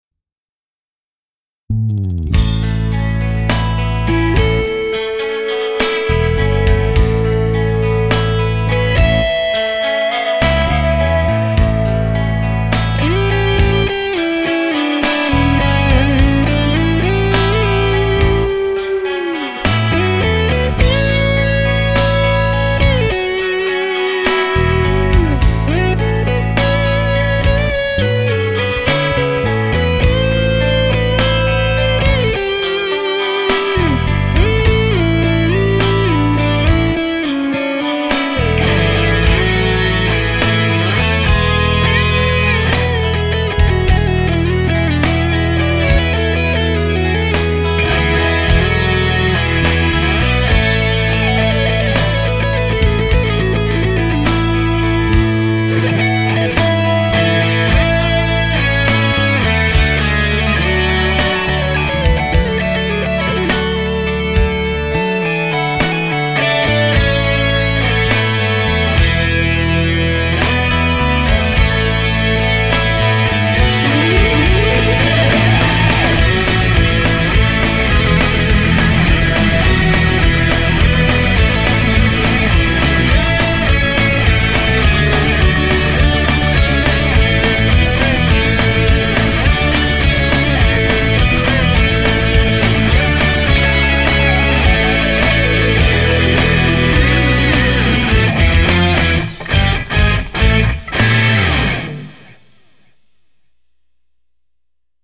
Instrumental
A guitar-oriented cover version of the well-known “Happy Birthday” song.